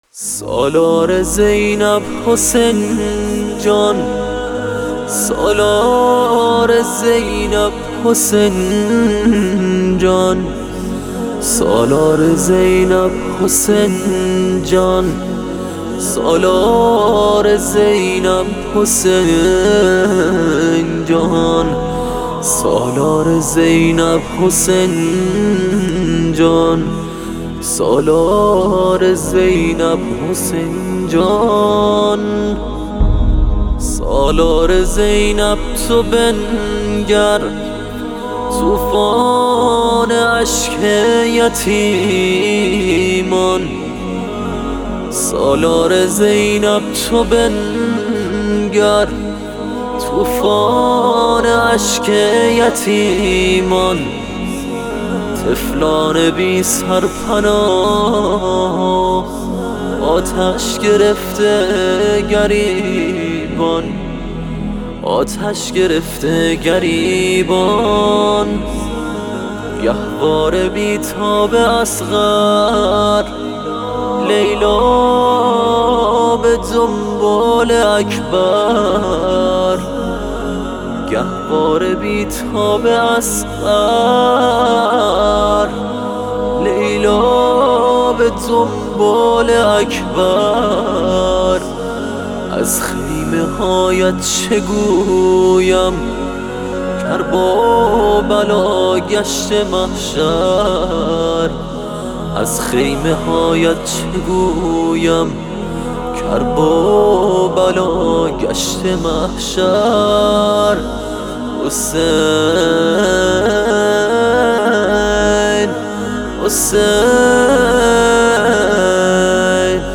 روضه استودیویی